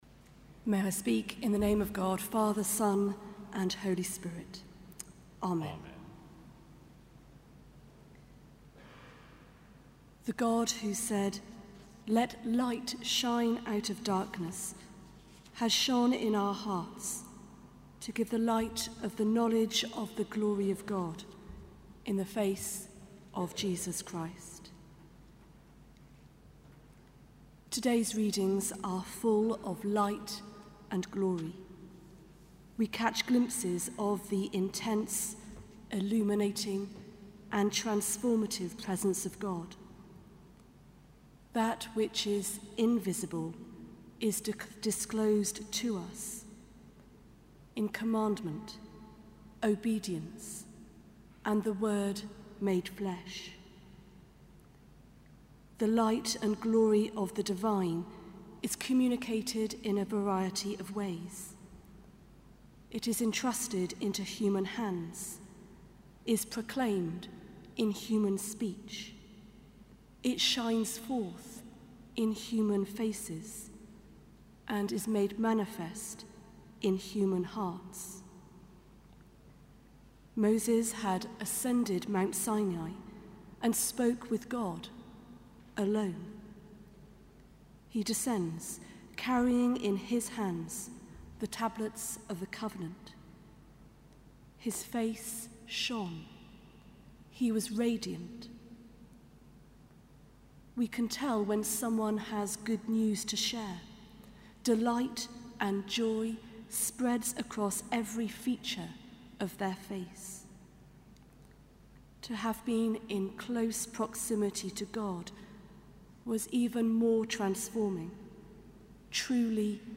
Sermon: Sunday before Lent